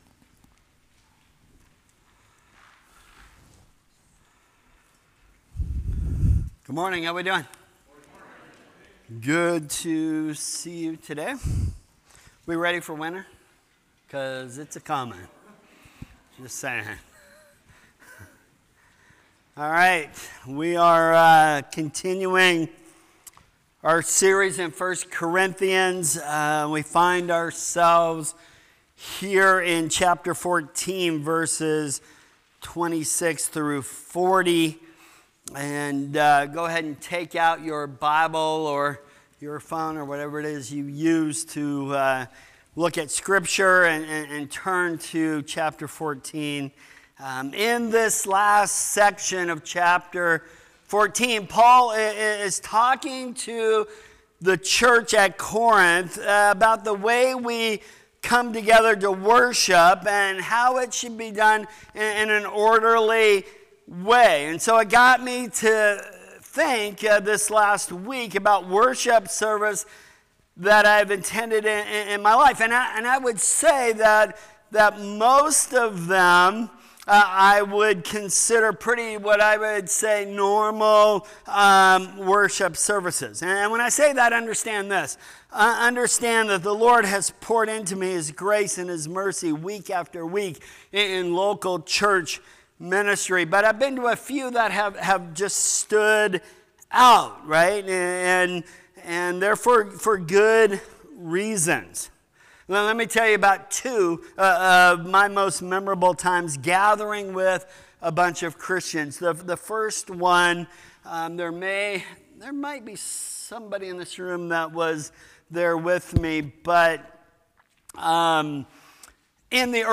Sermons | New Creation Fellowship